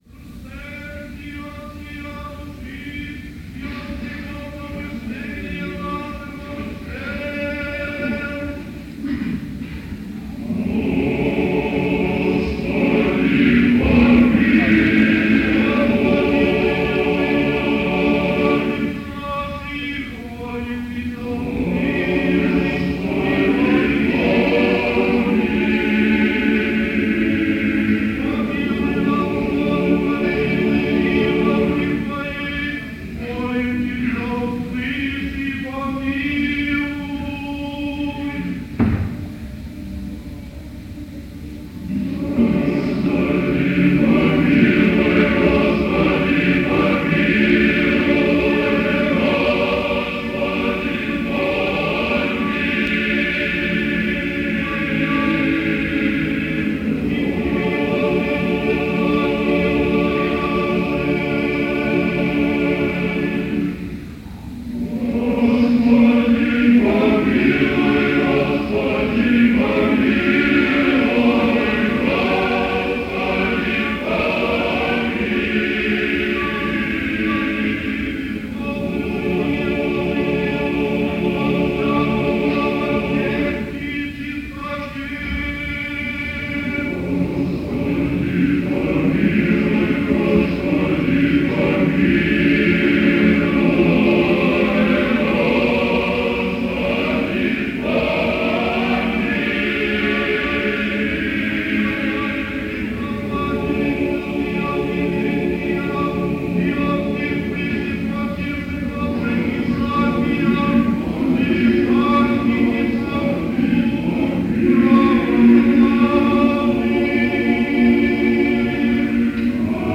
Всенощное бдение накануне Недели мясопустной
в Свято-Троицкой Сергиевой Лавры
Хор Свято-Троицкой Сергиевой Лавры